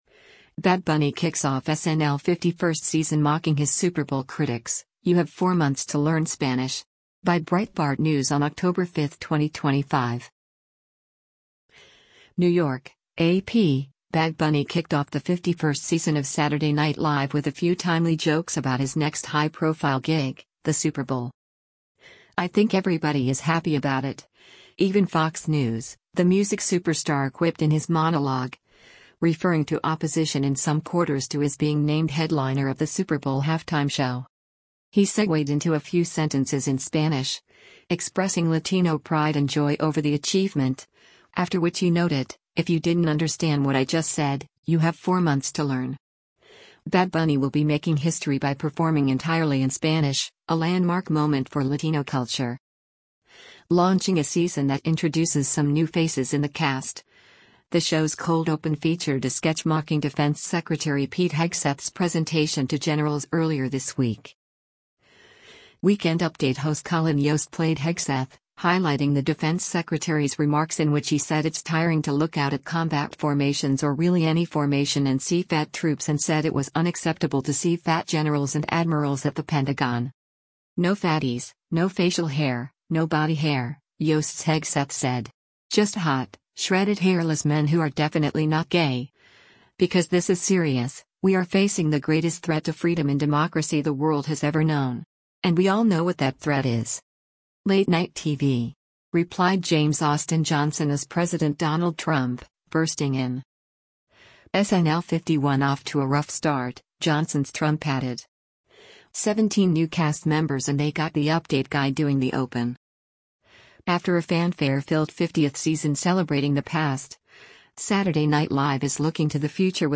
“I think everybody is happy about it — even Fox News,” the music superstar quipped in his monologue, referring to opposition in some quarters to his being named headliner of the Super Bowl halftime show.